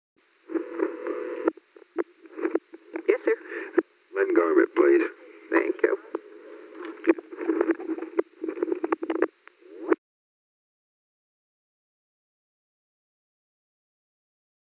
Location: White House Telephone
Alexander M. Haig talked with the White House operator.